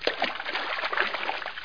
00219_Sound_Paddle.mp3